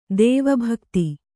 ♪ dēva bhakta